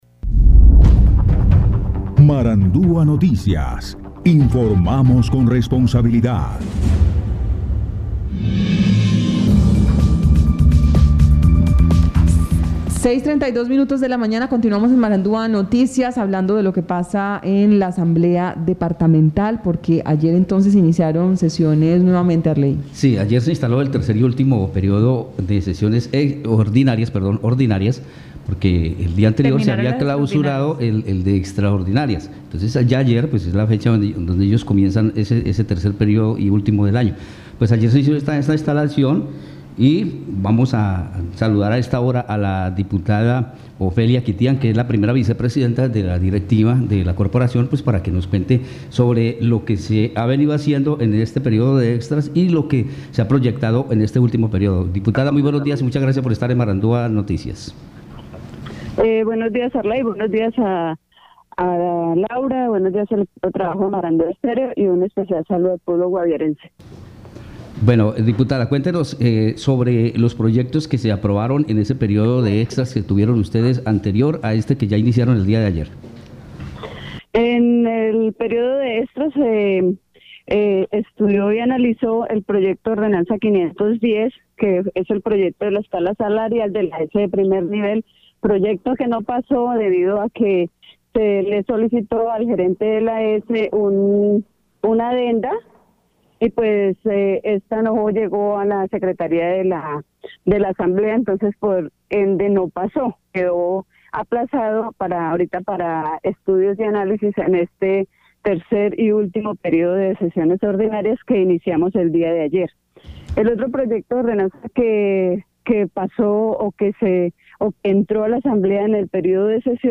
Escuche a Ofelia Quitián y a Alexander García, diputados del Guaviare.